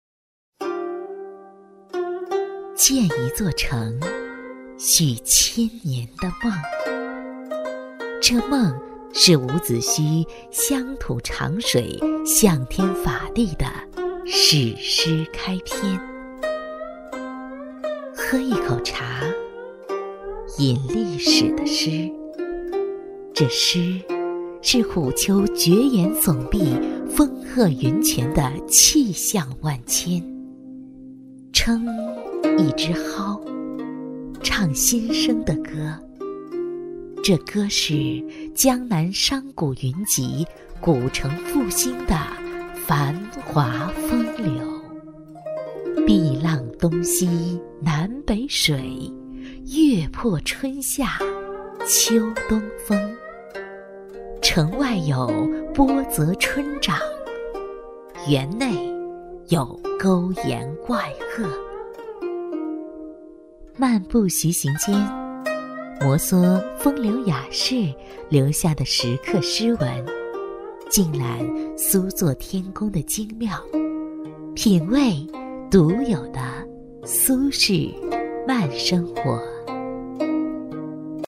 配音风格： 感情丰富，自然活力
【专题】建一座城